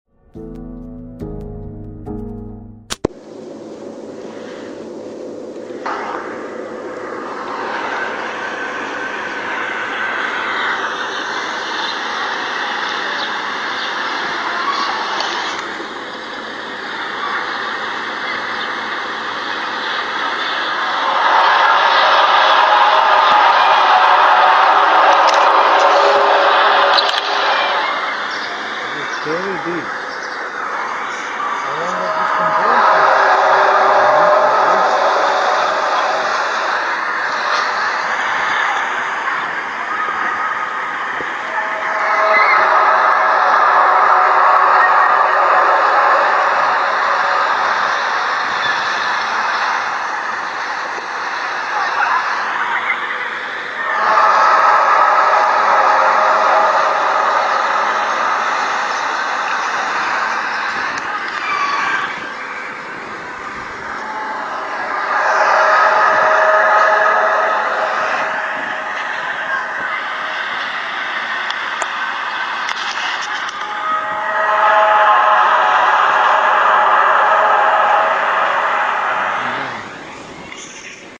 Loggers in Conklin, Alberta recorded eerie sky trumpets deep in the woods. Birds react violently. Unfiltered, real audio.